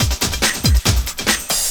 04 LOOP06 -R.wav